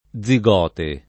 vai all'elenco alfabetico delle voci ingrandisci il carattere 100% rimpicciolisci il carattere stampa invia tramite posta elettronica codividi su Facebook zigote [ +z i g0 te ] o zigoto [ +z i g0 to ] s. m. (biol.)